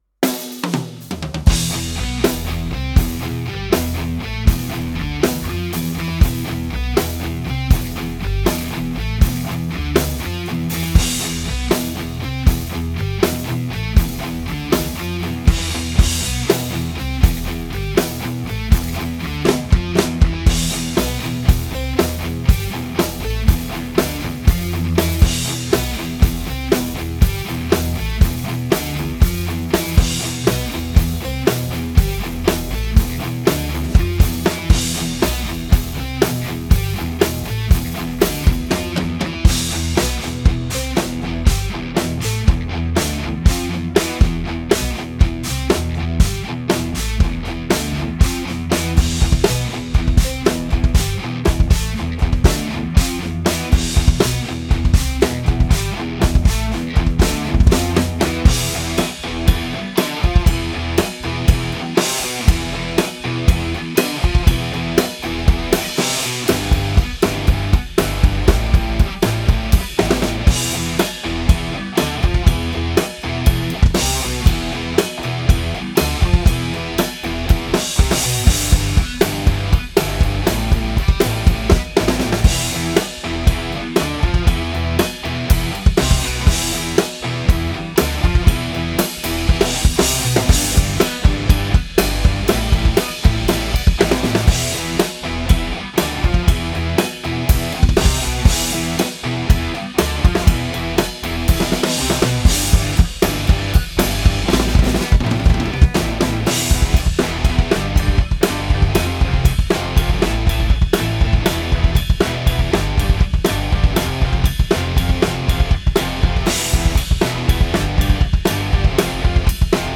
Playalong und Performance